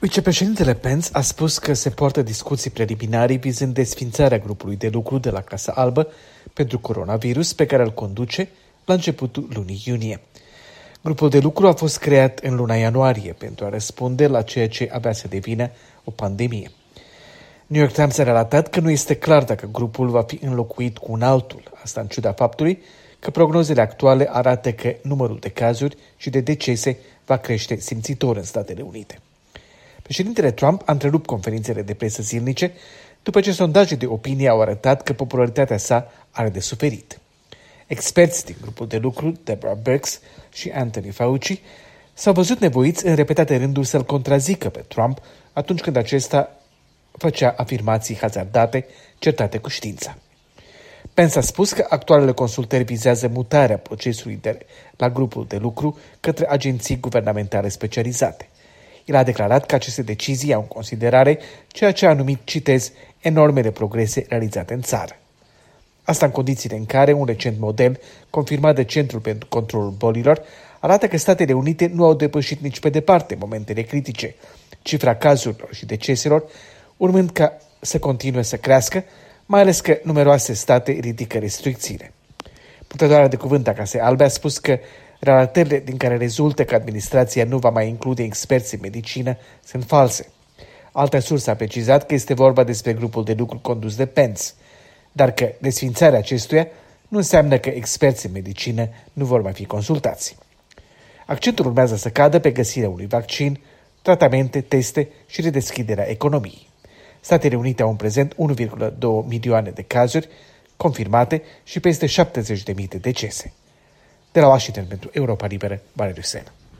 Corespondență de la Washington: vicepreședintele Mike Pence